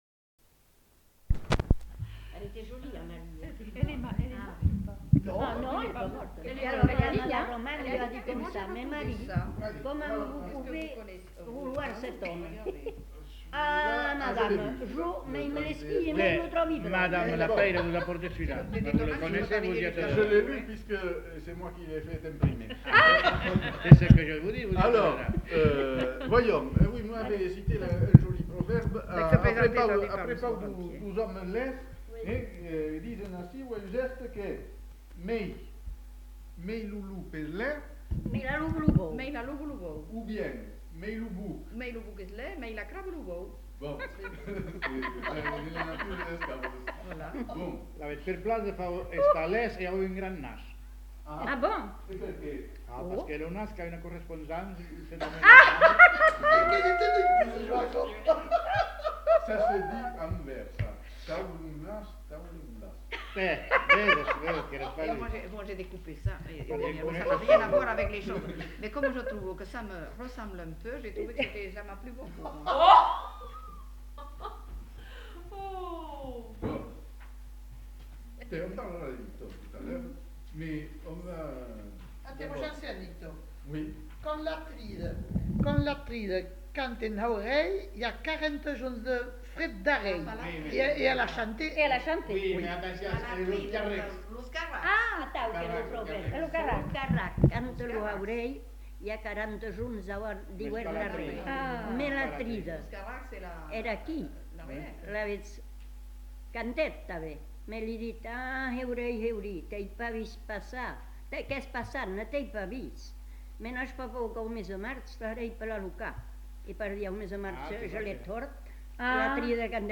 Proverbes
Lieu : Uzeste
Effectif : 1
Type de voix : voix de femme
Production du son : récité
Classification : proverbe-dicton